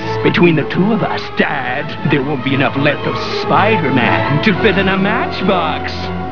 Carnage Sound Bytes!
From the Spider-Man animated series.